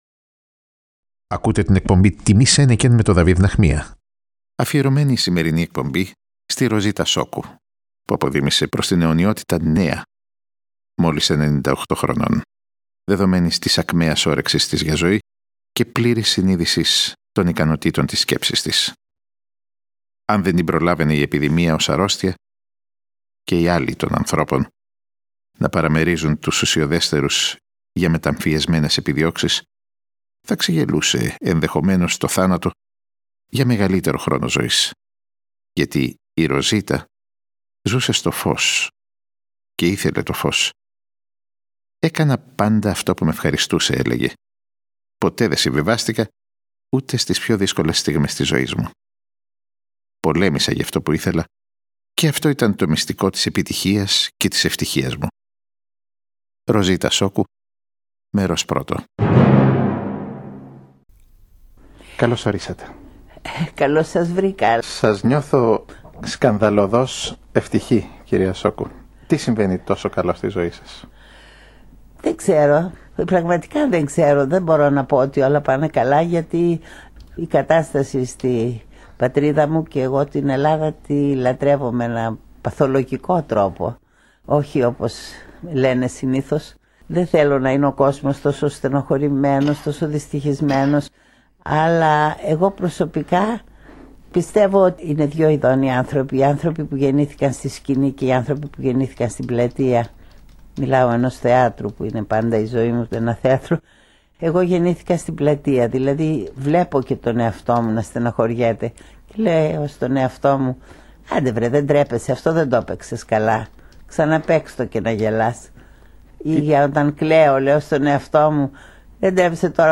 Μία χειμαρρώδης, διδακτικά αφηγηματική παρακαταθήκη από μία αναντικατάστατη προσωπικότητα του εικοστού αιώνα που αναχώρησε. Μία αυτοβιογράφηση που διανύει ολόκληρο τον Εικοστό Αιώνα για να φτάσει στο σήμερα φιλτραριζόμενη μέσα απ’ τις ζωές των σημαντικότερων πρωταγωνιστών του.